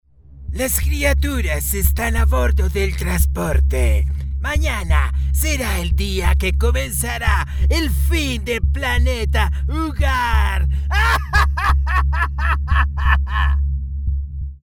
Locutor Colombiano, entregando una voz que interpreta pasión, ternura, seguridad, respaldo.. lo que usted esta buscando.
kolumbianisch
Sprechprobe: Sonstiges (Muttersprache):